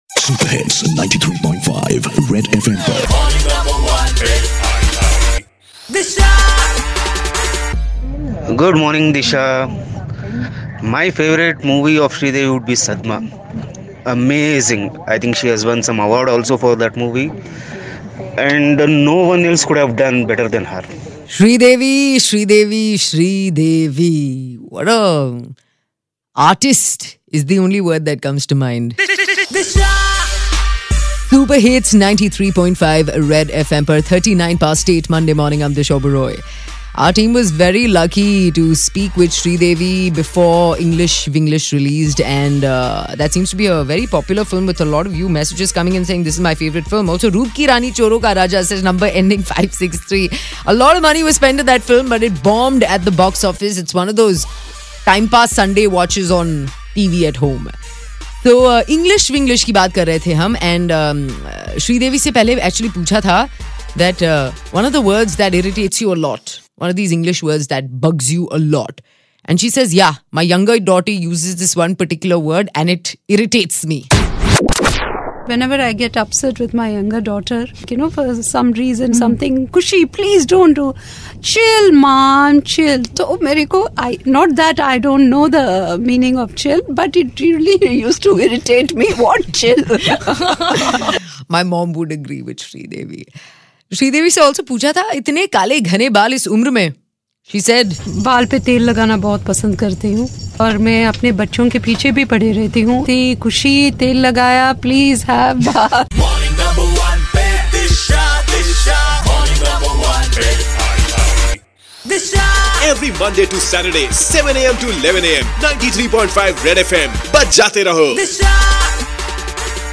Sridevi's Last Interview